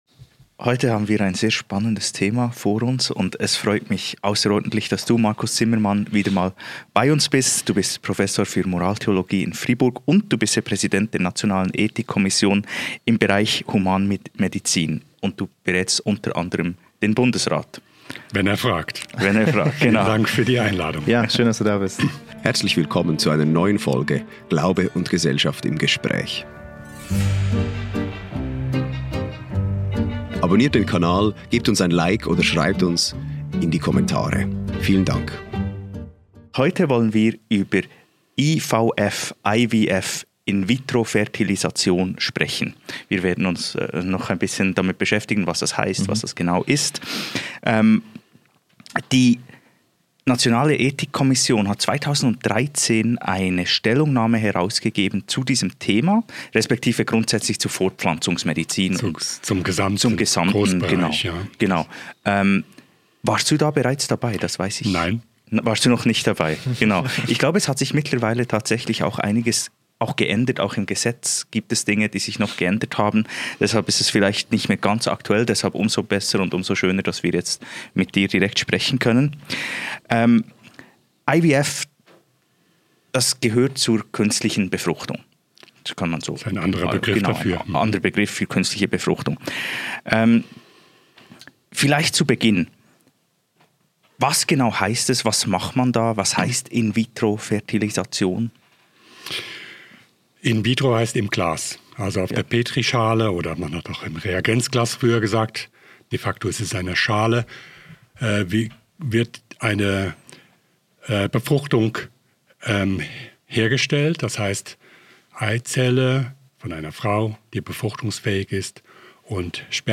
213. Kritische Fragen zur künstlichen Befruchtung [TALK] - mit. Dr. Markus Zimmermann ~ Glaube und Gesellschaft Podcast
Wir sprechen mit dem Präsidenten der Nationale Ethikkommission im Bereich der Humanmedizin (NEK), Dr. Markus Zimmermann, über seine Einschätzung zum Thema In vitro fertilization (IVF).